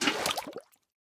fill_fish1.ogg